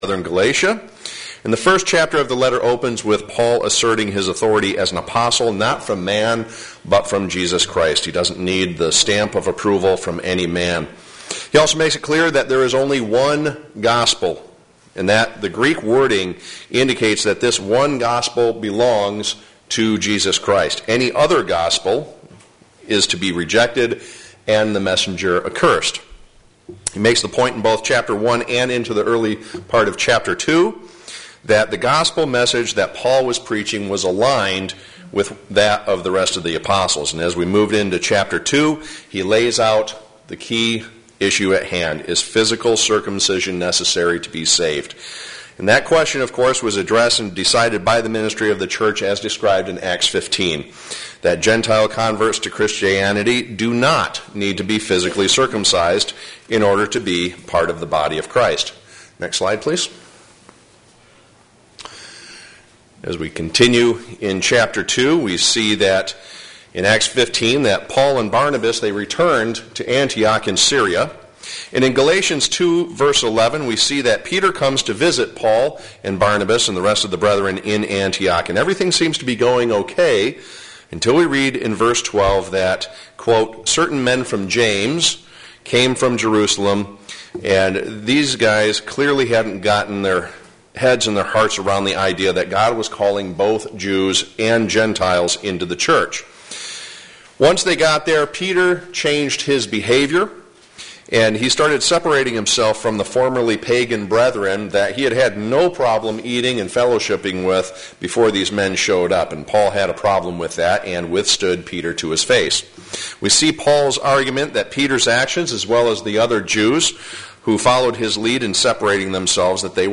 Galatians Bible Study: Part 6